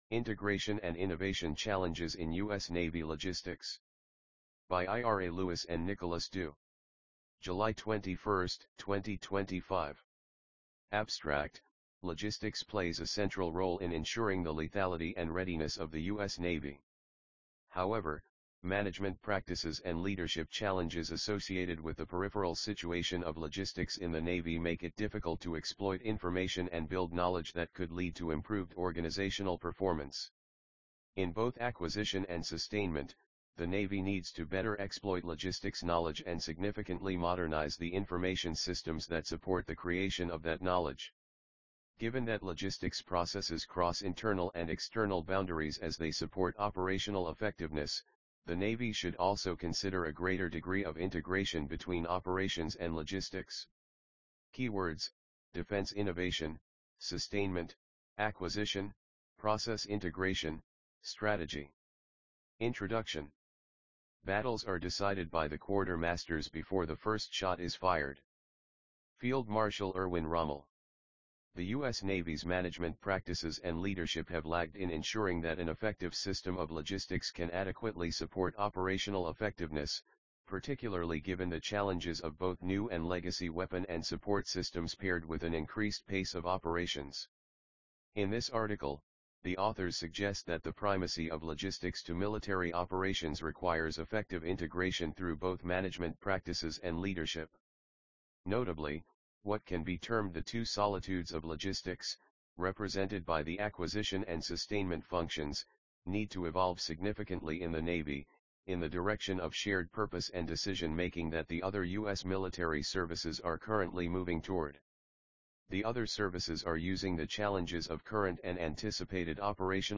EXP_Integration and Innovation Challenges in U_S_ Navy Logistics_AUDIOBOOK.mp3